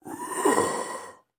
skeleton-woke-up.ogg